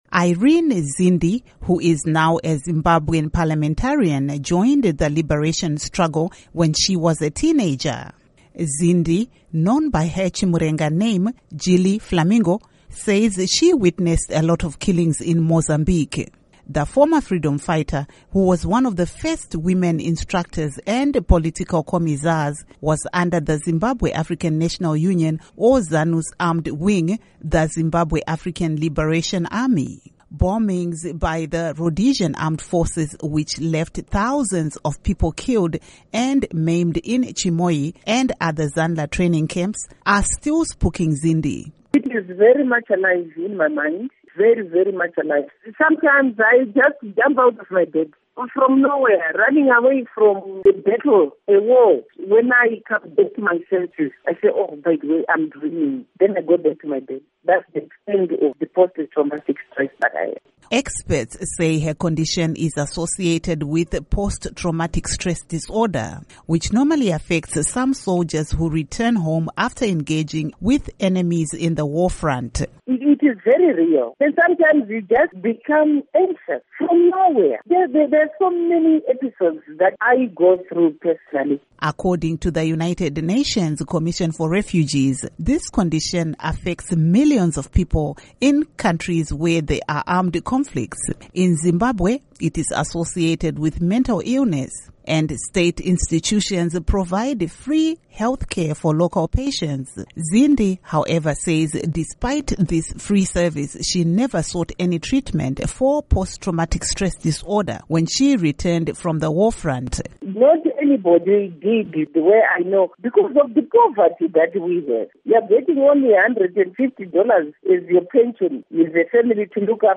Report on Post-Traumatic Stress Disorder